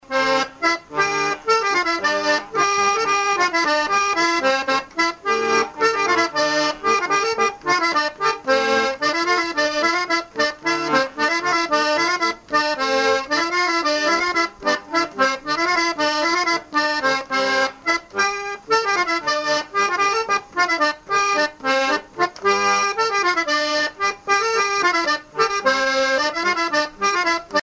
Chants brefs - A danser
danse : scottish
musique à danser à l'accordéon diatonique
Pièce musicale inédite